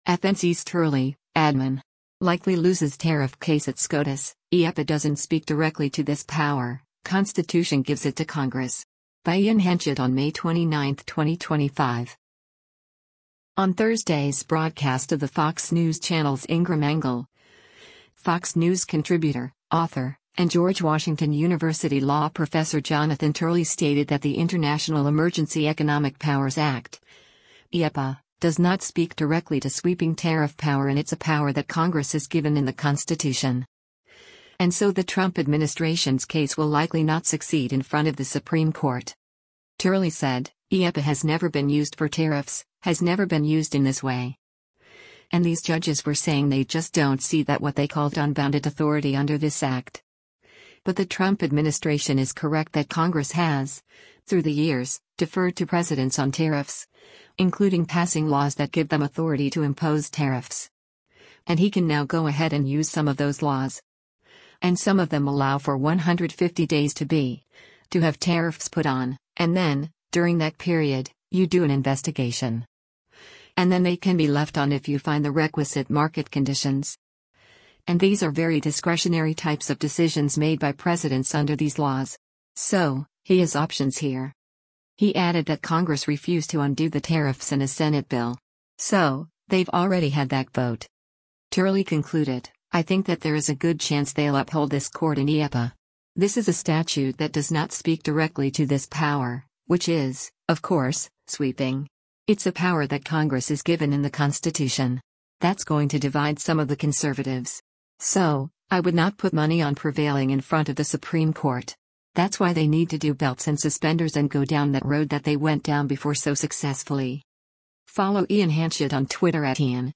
On Thursday’s broadcast of the Fox News Channel’s “Ingraham Angle,” Fox News Contributor, author, and George Washington University Law Professor Jonathan Turley stated that The International Emergency Economic Powers Act (IEEPA) “does not speak directly to” sweeping tariff power and “It’s a power that Congress is given in the Constitution.”